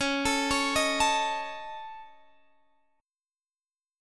Listen to Dbsus2#5 strummed